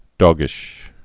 (dôgĭsh, dŏgĭsh)